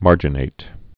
(märjə-nāt)